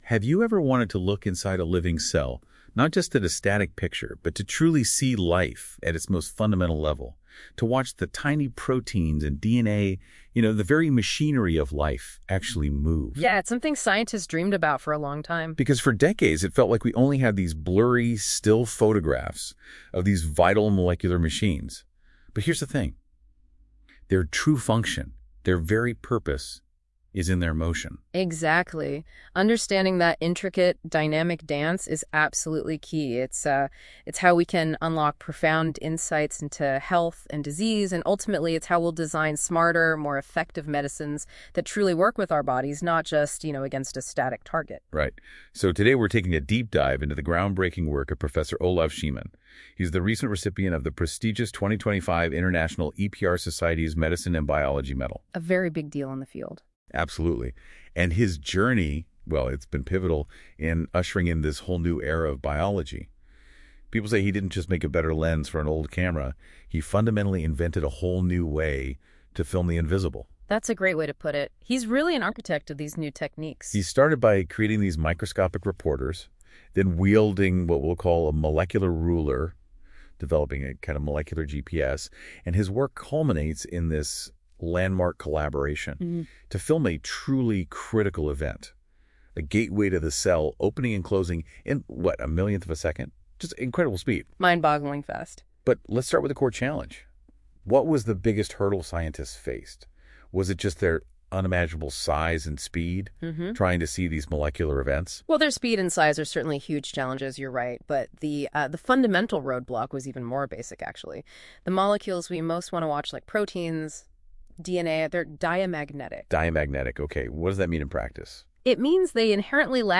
An AI-generated Podcast